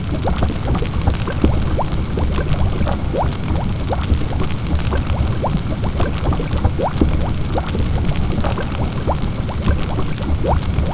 fire_lava1.wav